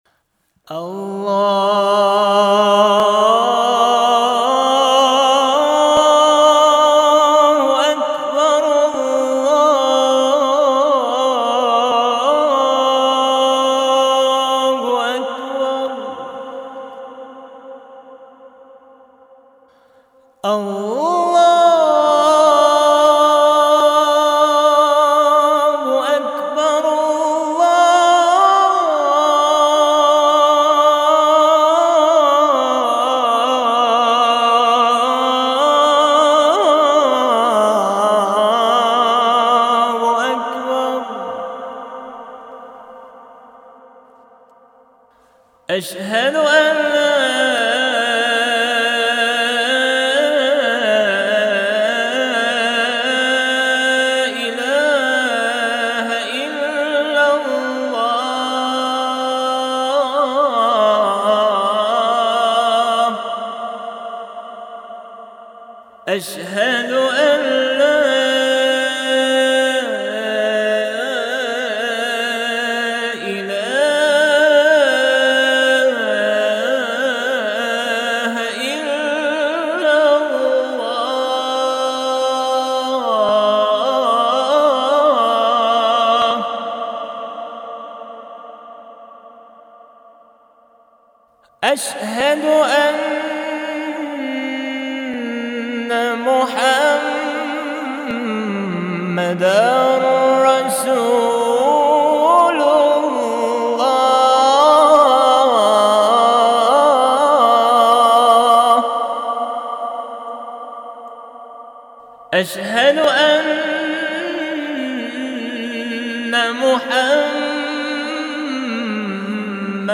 اذان آرامش بخش و احساسی
بهترین اذان صوتی آرامش بخش و احساسی + متن اذان